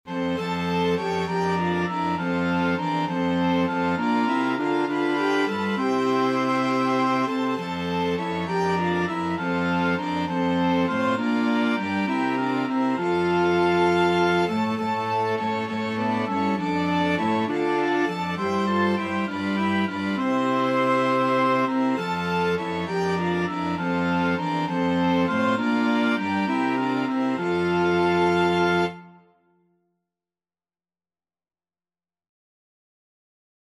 Christmas Christmas Flexible Mixed Ensemble - 4 Players Sheet Music It Came Upon the Midnight Clear
Alto Saxophone
Flute
Oboe
Eb Alto Clarinet
6/8 (View more 6/8 Music)
F major (Sounding Pitch) (View more F major Music for Flexible Mixed Ensemble - 4 Players )
Traditional (View more Traditional Flexible Mixed Ensemble - 4 Players Music)